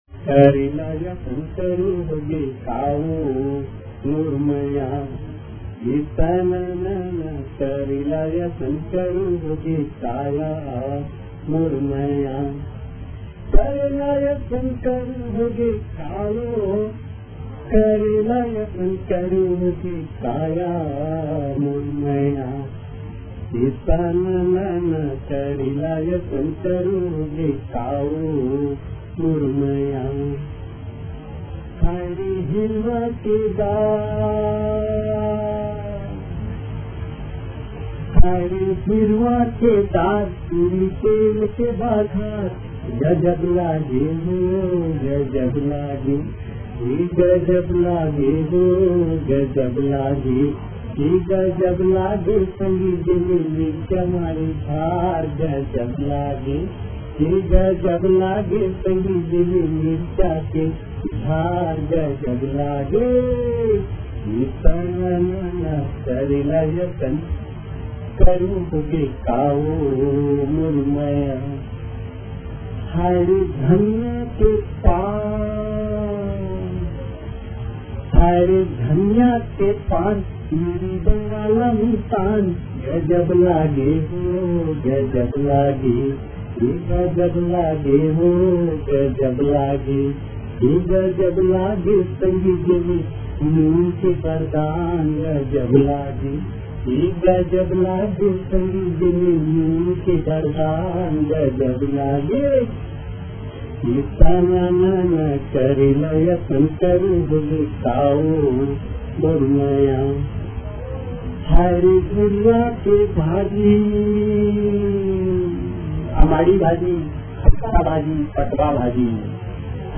Chhattisgarh
करमा गीत गाते वक्त मादंर बजाया जाता है।
करमा गीत